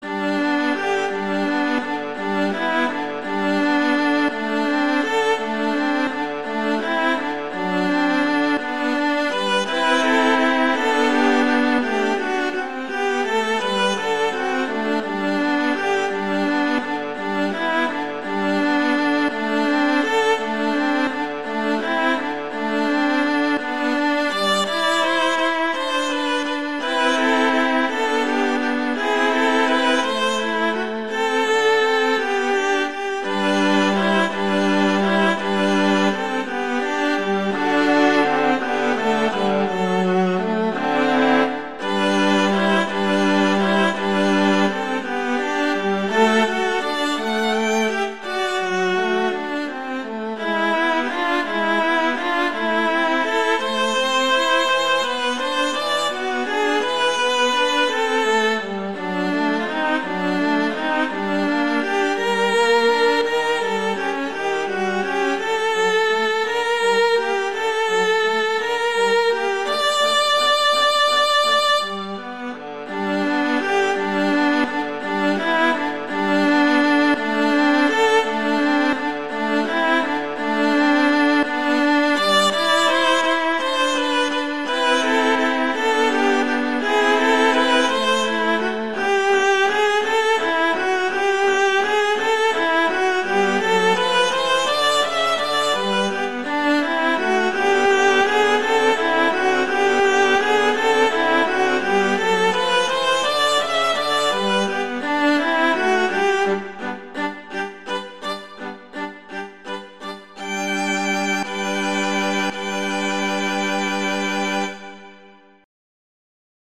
classical, french